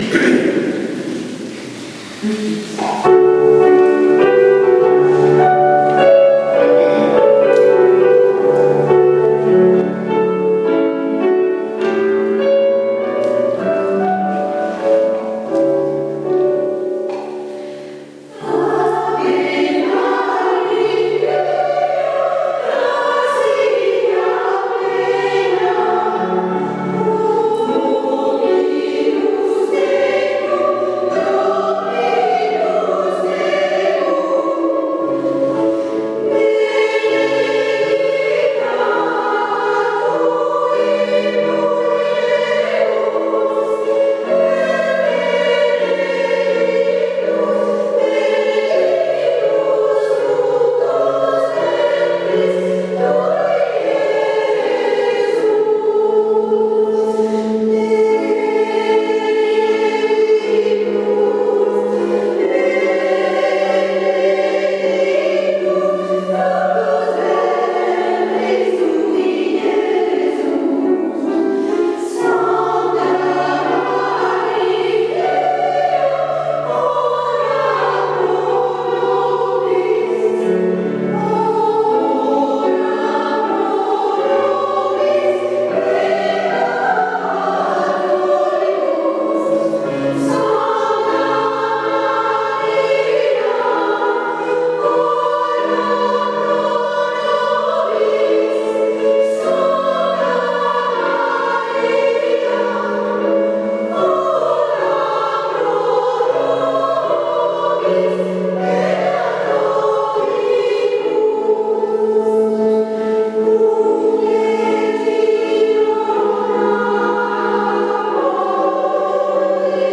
Mp3 Ave Maria Mozart Valsaintes 2012